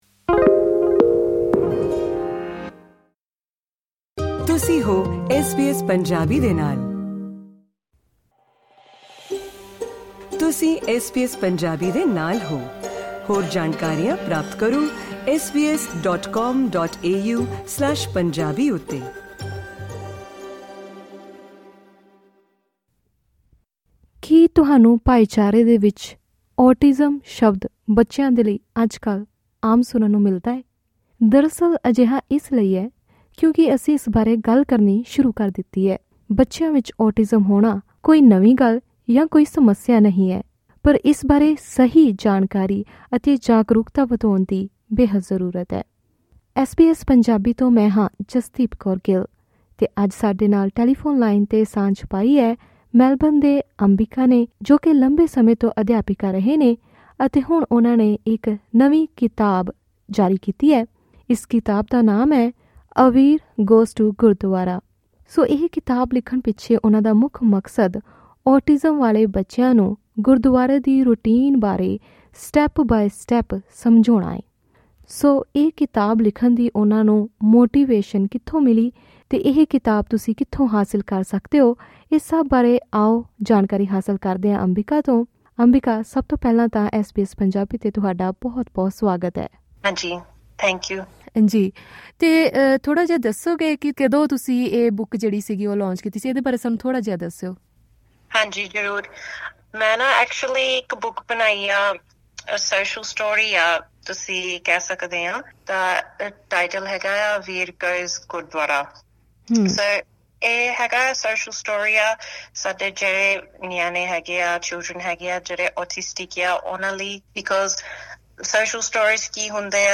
ਪੂਰੀ ਗੱਲਬਾਤ ਸੁਣੋ ਇਸ ਪੋਡਕਾਸਟ ਰਾਹੀਂ..